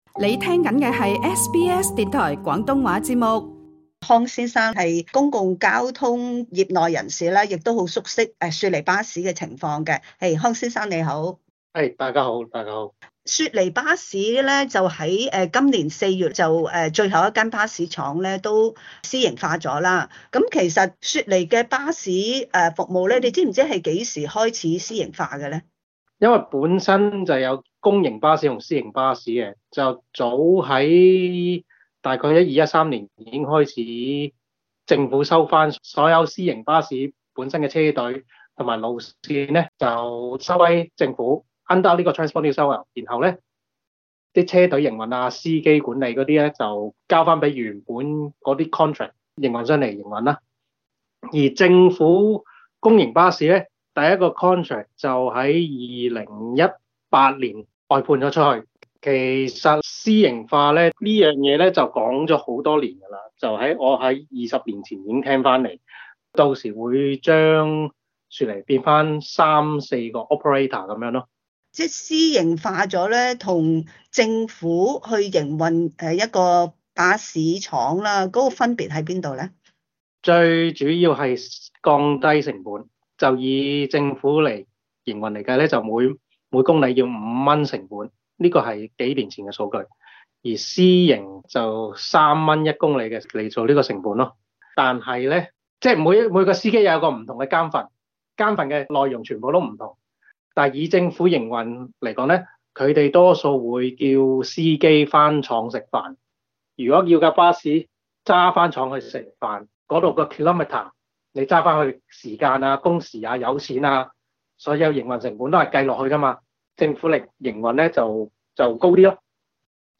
詳情請聽今集【社區專訪】。